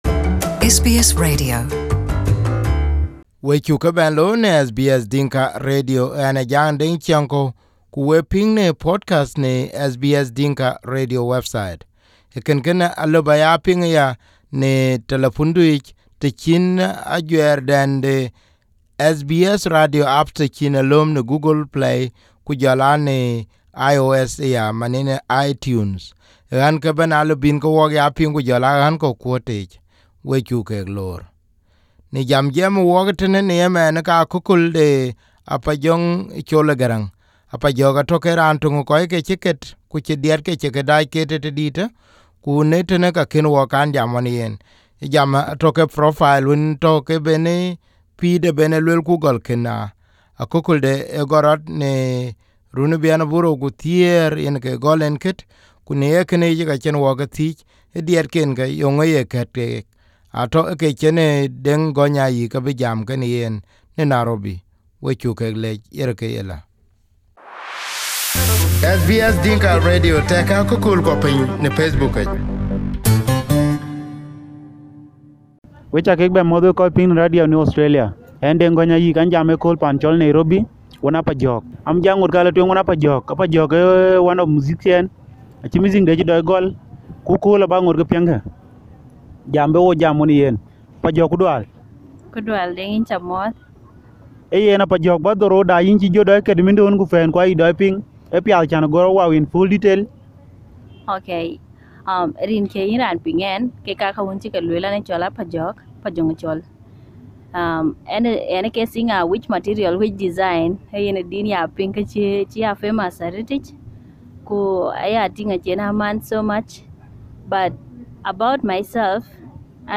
She started her music career in 2010 while she was still a primary school student. this is her first interview with SBS Dinka Radio in Nairobi.